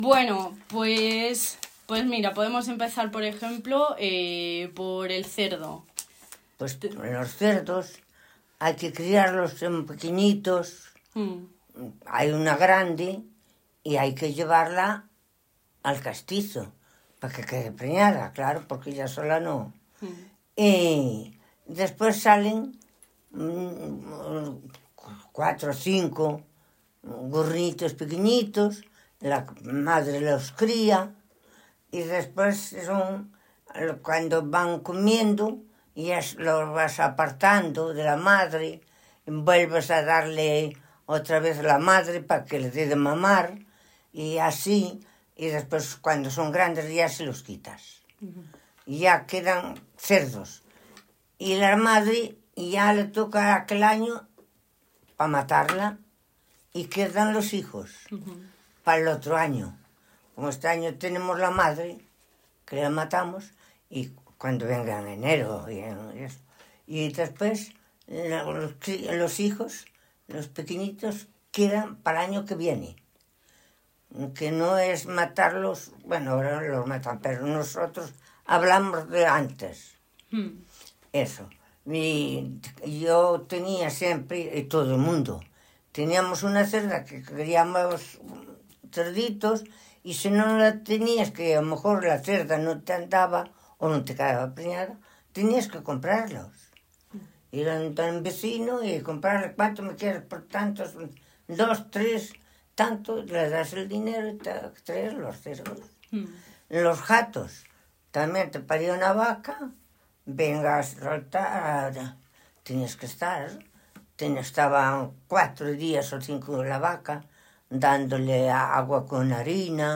Santa Colomba de Sanabria (Cobreros)
mujer